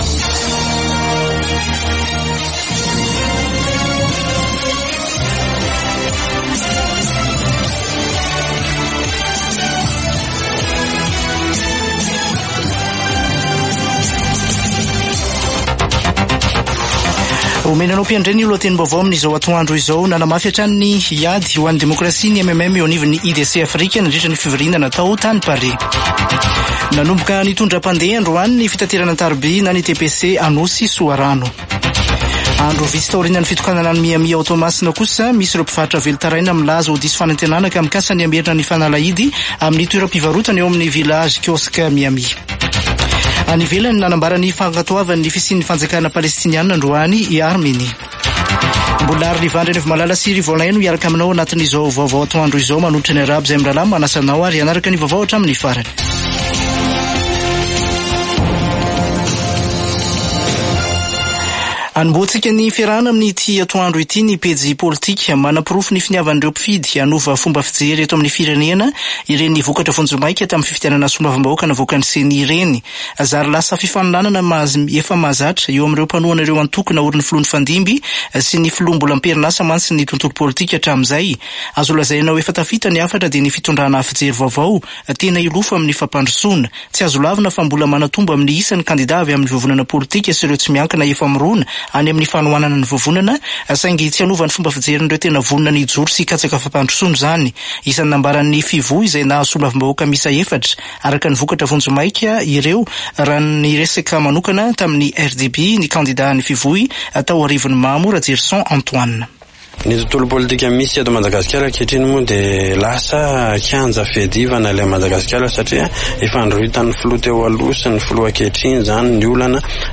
[Vaovao antoandro] Zoma 21 jona 2024